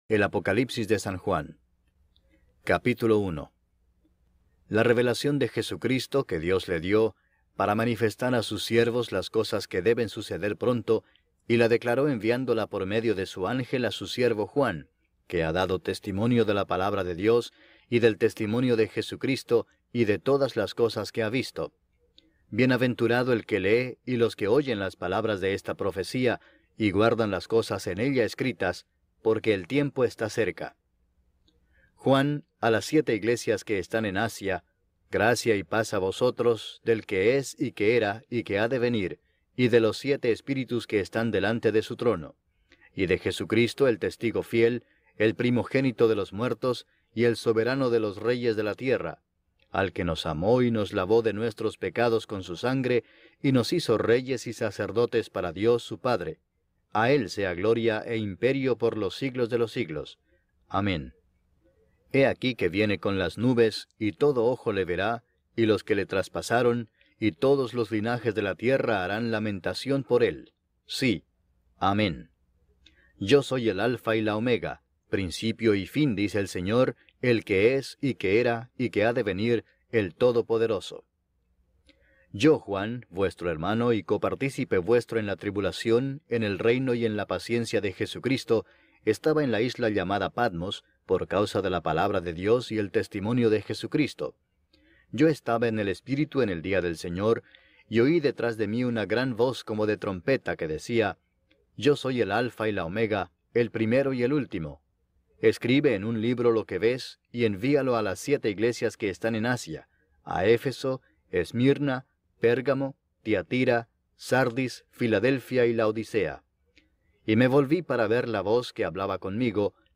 El evangelio de Marcos narrado de forma completa